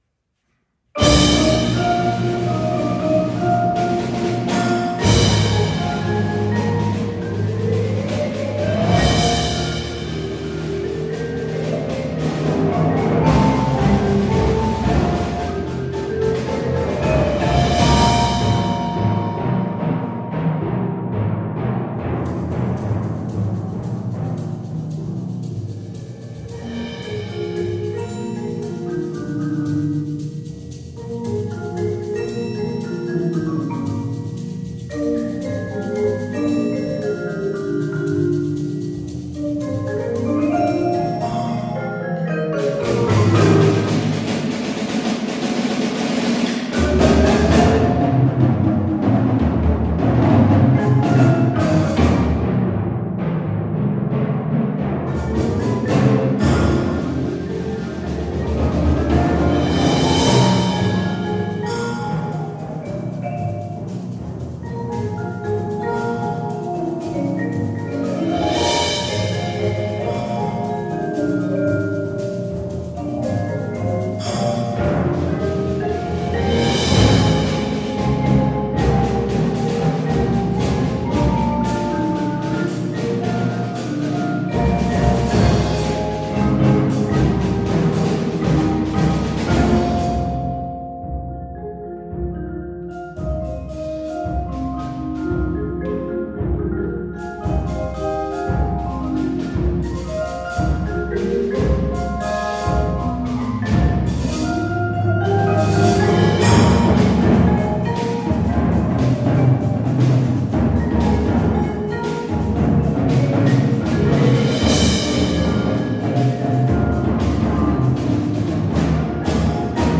Genre: Percussion Ensemble
Vibraphone 1
Marimba 1 [4.3-octave]
Timpani [5 drums]
Percussion 2: Small Bass Drum, Shaker, 4 Concert Toms